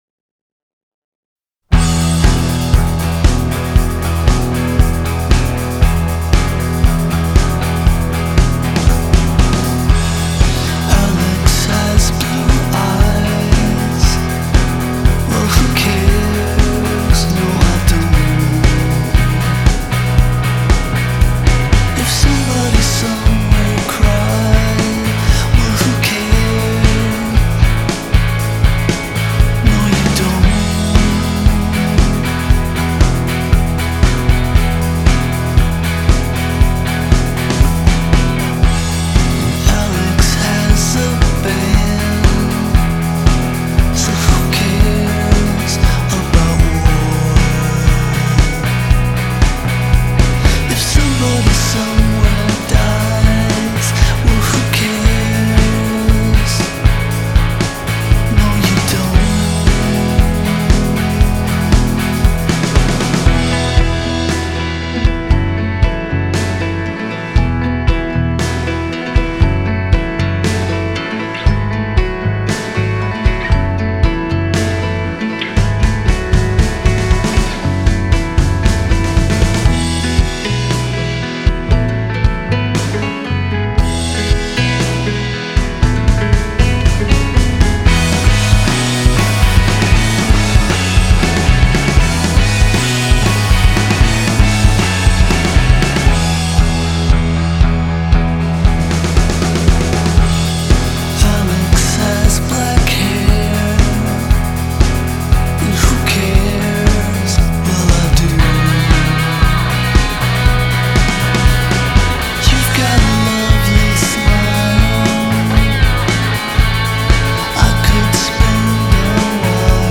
Genre: Indie, Rock